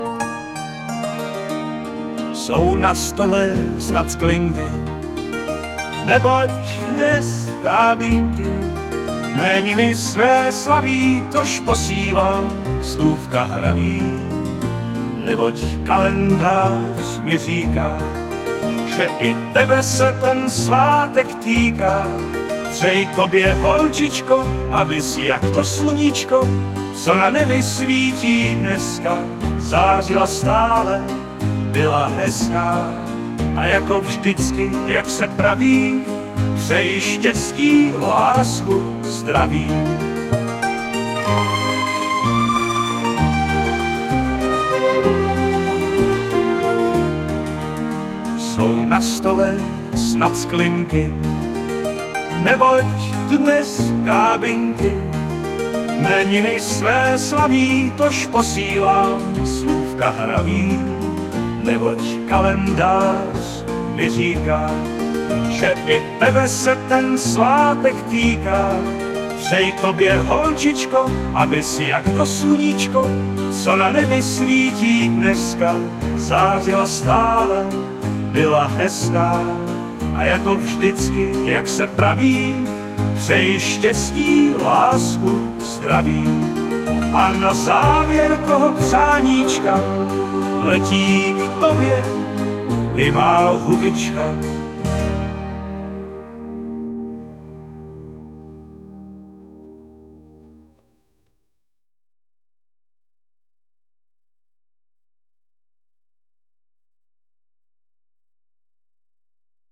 * hudba, zpěv: AI